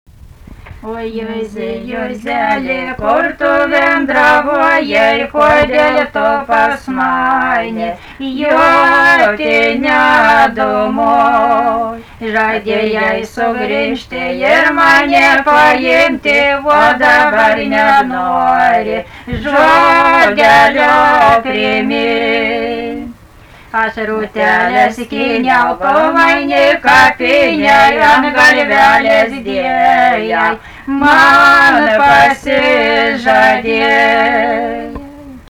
daina
vokalinis
2 balsai